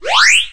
Heal1.ogg